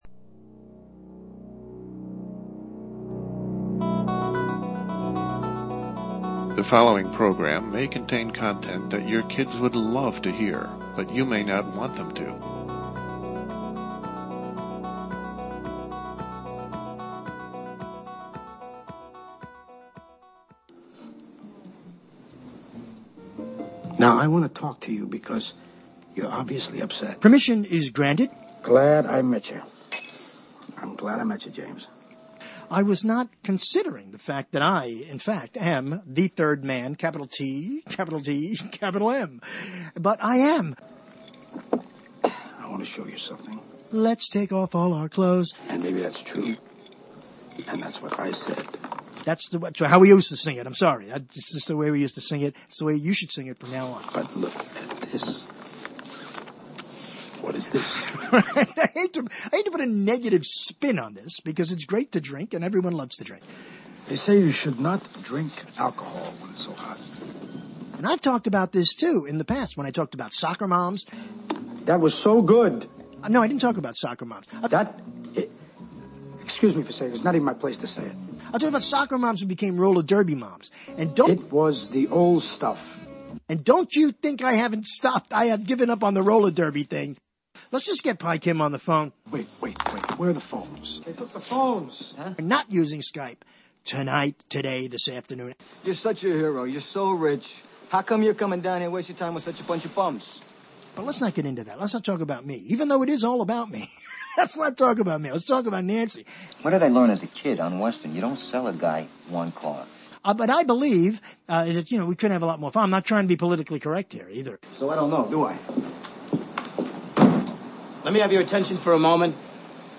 LIVE, Thursday, Jan. 3 at 9 p.m. LIVE. It’s the first show of the 20th season (but who’s counting?) and Y2K-plus-19 launches with new things, old things and all things in between.